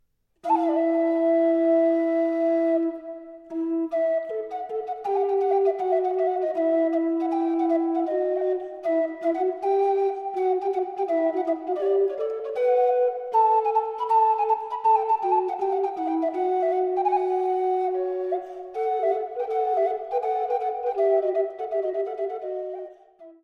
voor sopraan- en tenorblokfluit.